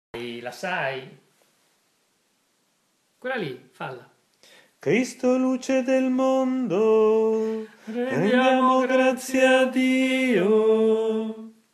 Il diacono o, in sua assenza, il sacerdote prende il cero pasquale e, tenendolo elevato, da solo canta: